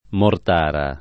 Mortara [ mort # ra ]